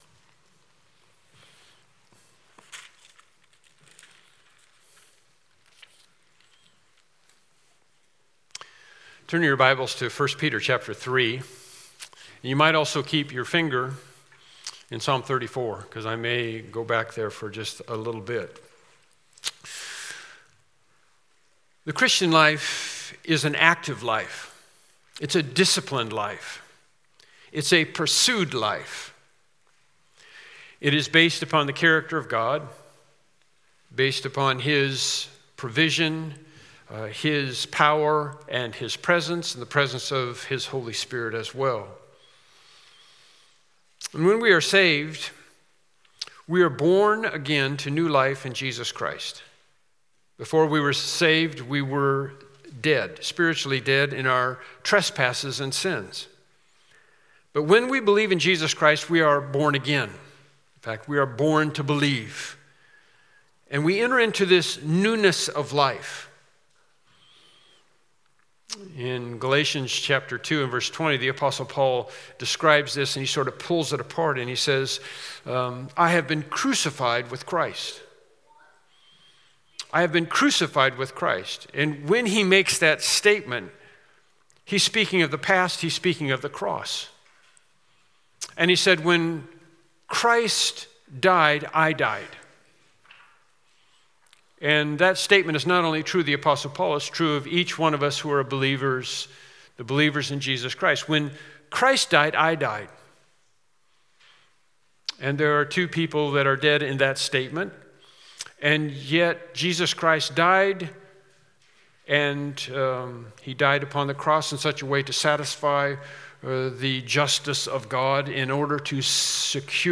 Bible Text: 1 Peter 3:9-12 | Preacher